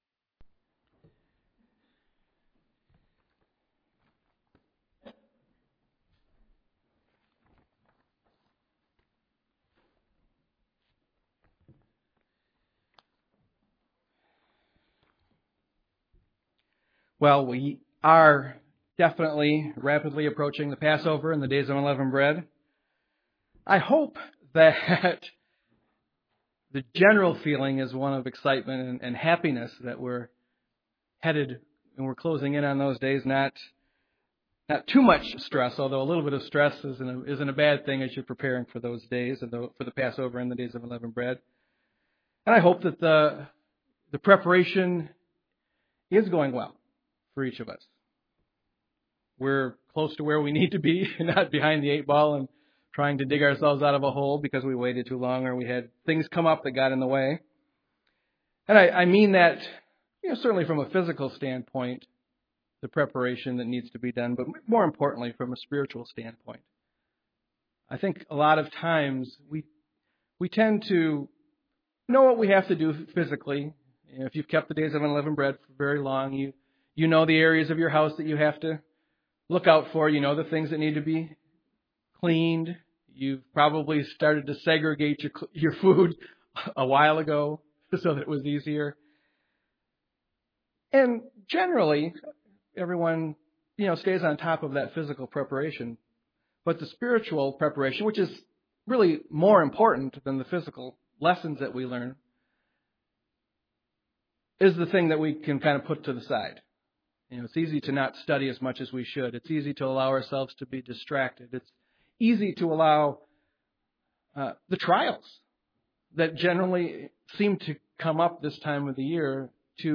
Given in Grand Rapids, MI
UCG Sermon Studying the bible?